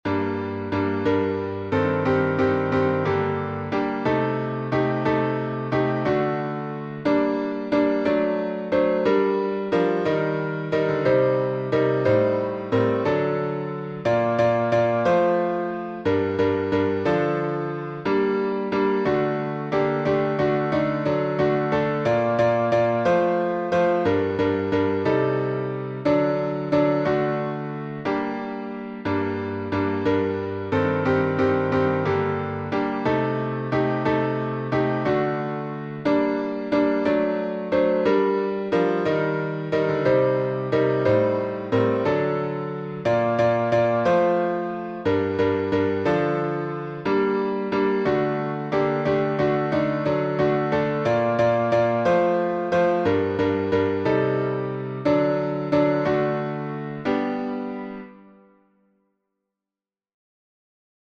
Ancient Hebrew Melody
Key signature: G minor (2 flats)